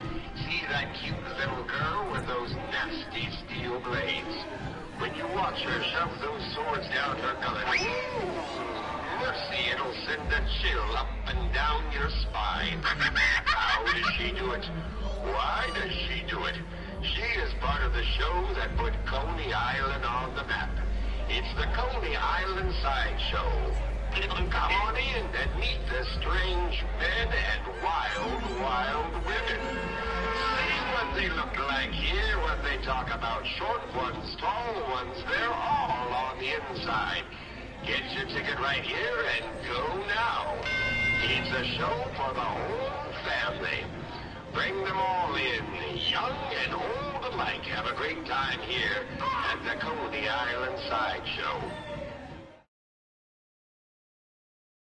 描述：2008年在科尼岛的现场录音
Tag: 娱乐 锥Y 游艺场 海岛 公园 杂耍 扬声器 语音